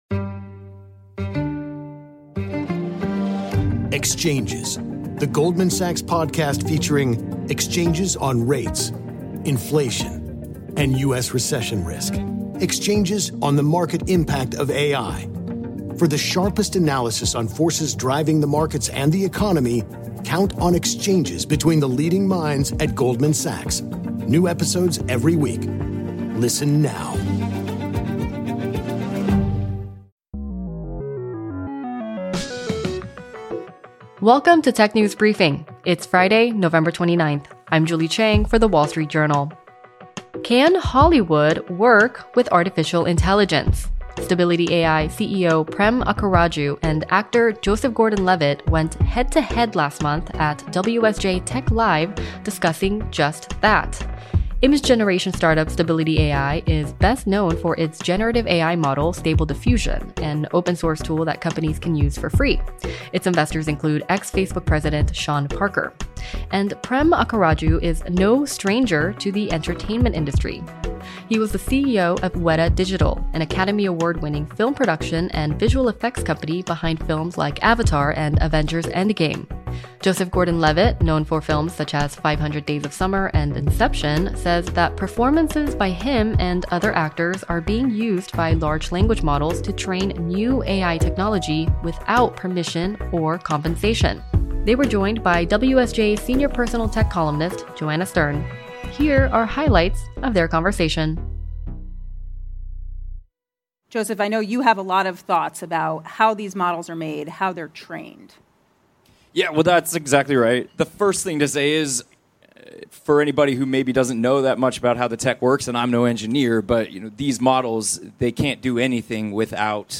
We play you highlights of that conversation.